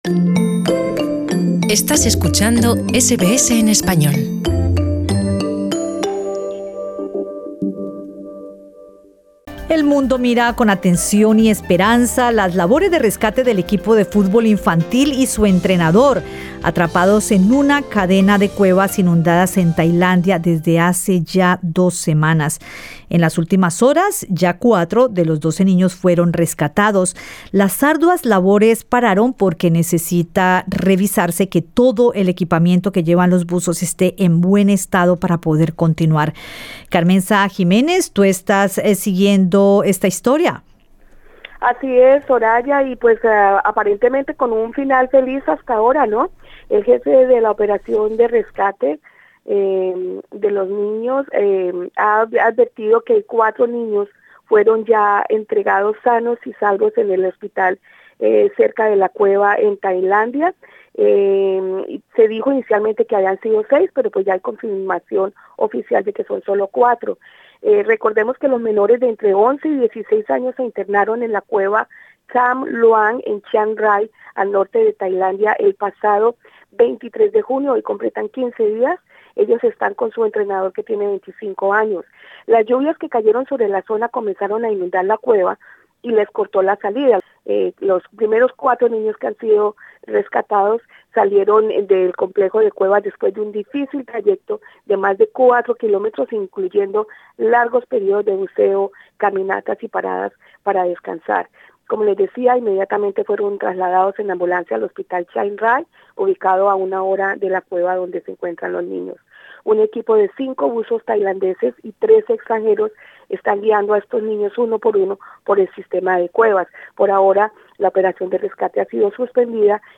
en entrevista con SBS español